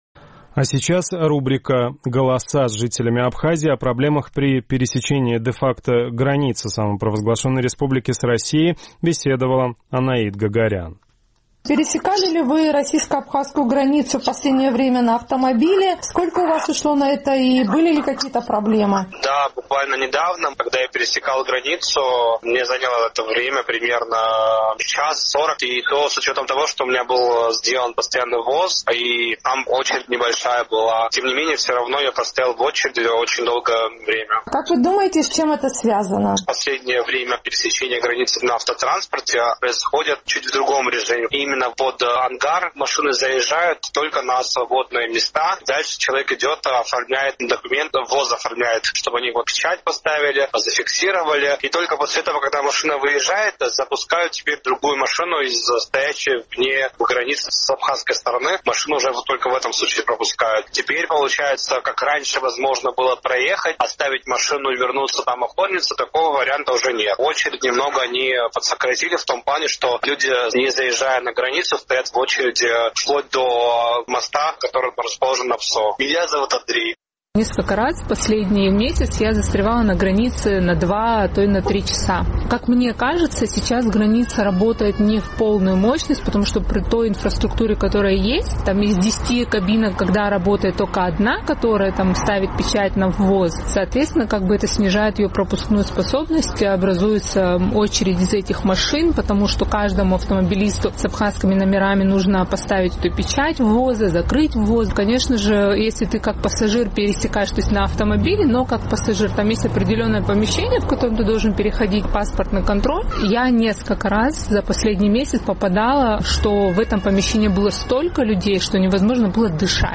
О проблемах при пересечении КПП «Псоу» – в нашем традиционном сухумском опросе.